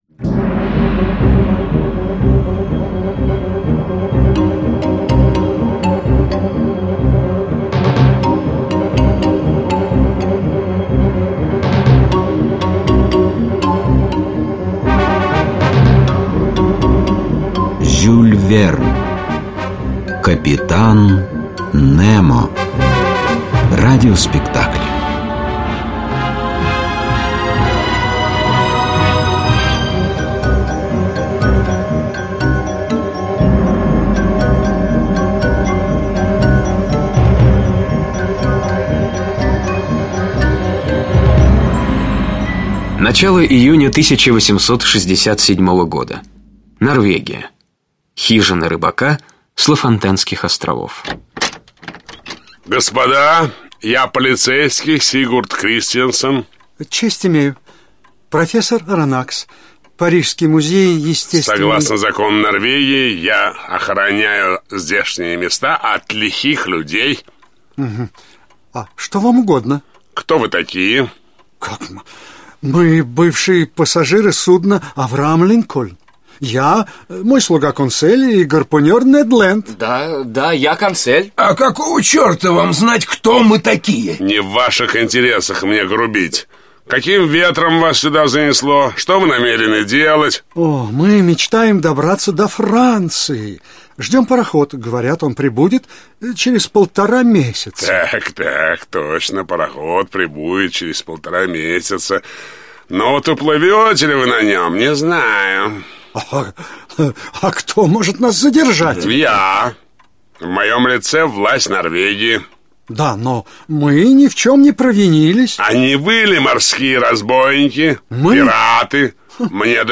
Аудиокнига Капитан Немо (спектакль) | Библиотека аудиокниг